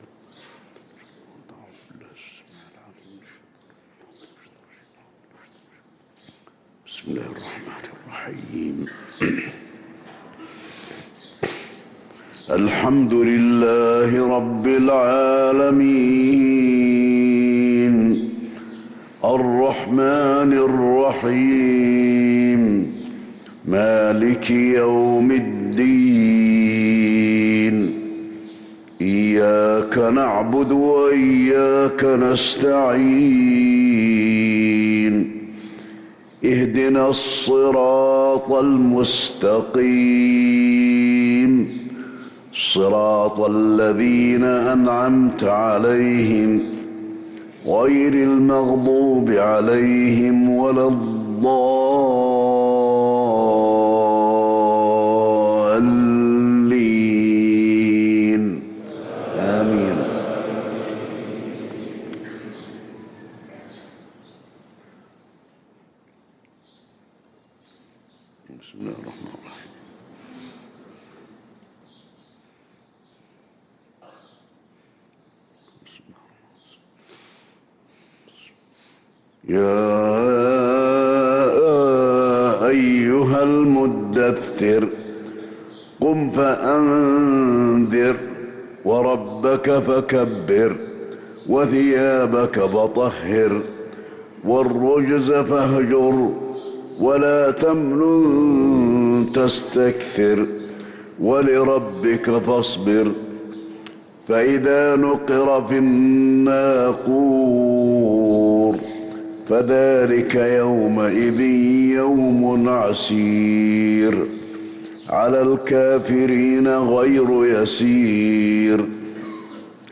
صلاة الفجر للقارئ علي الحذيفي 24 ربيع الأول 1445 هـ
تِلَاوَات الْحَرَمَيْن .